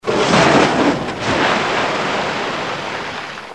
איוושת גלי ים| יש למישהוא?